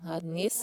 La riprova si ha ascoltando le registrazioni al contrario: